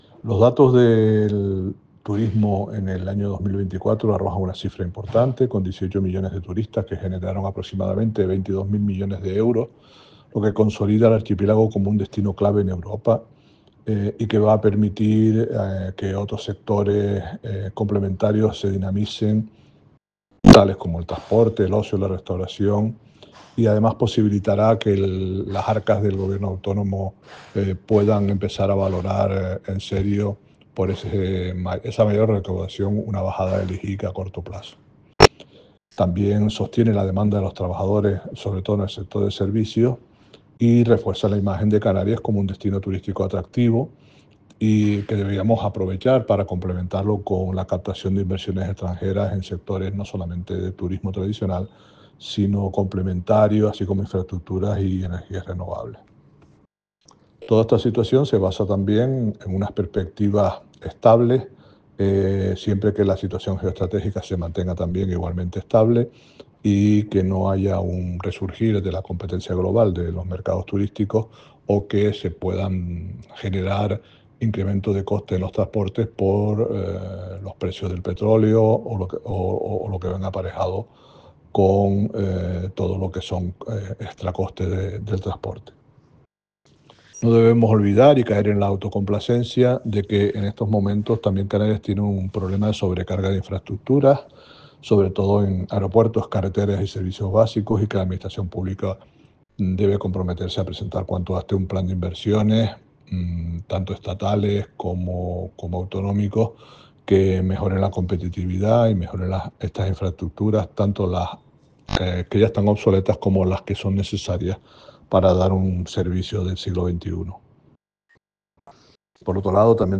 DECLARACIONES-AUDIO-SOBRE-DATOS-TURISMO-ESPANA-2024-mp3cut.net_.mp3